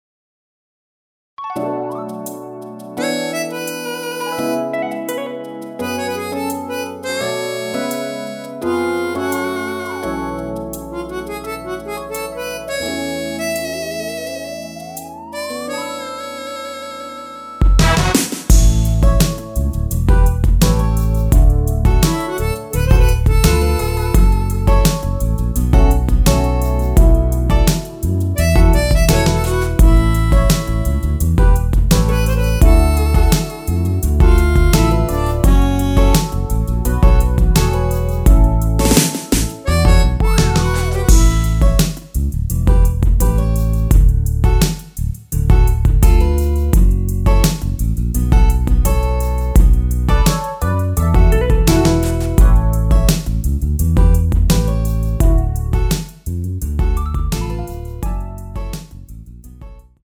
원키에서(-5)내린 MR입니다.
앞부분30초, 뒷부분30초씩 편집해서 올려 드리고 있습니다.
중간에 음이 끈어지고 다시 나오는 이유는